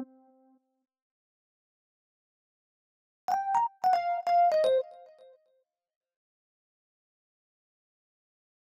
30 ElPiano PT4.wav